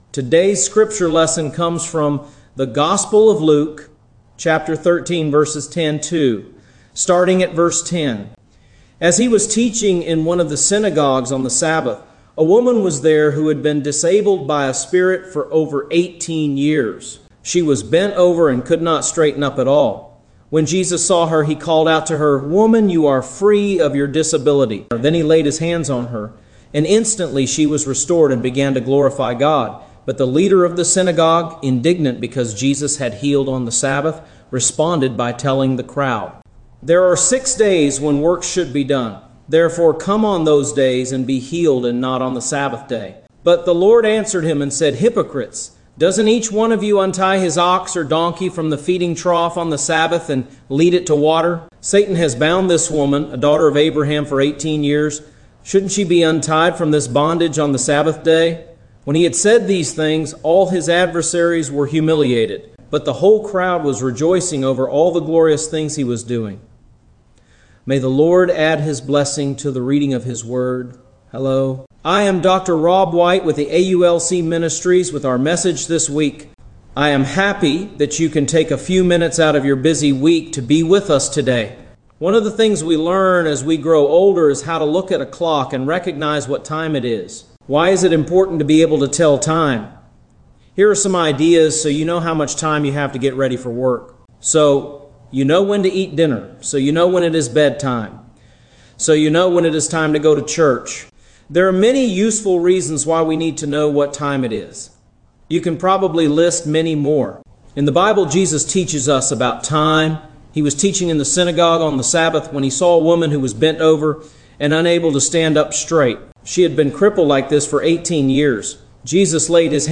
" Topical Series " Jesus Come Quickly Sermon Notes